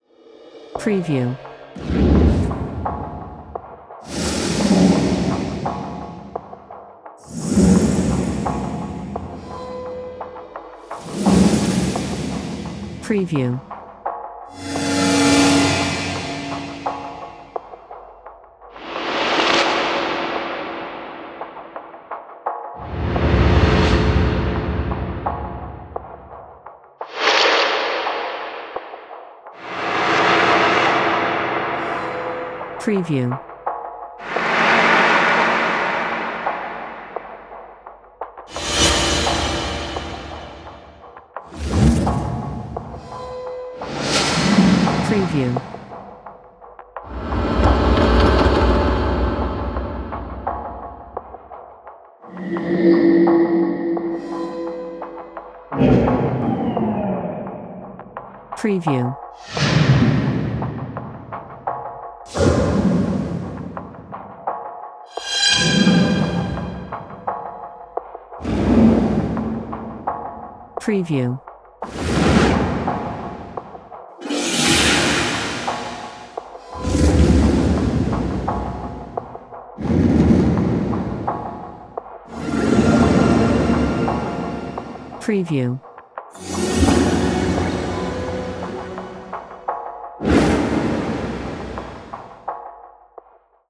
INTENSE TRANSITION SOUNDS WATERMARKED WITH BEATS.mp3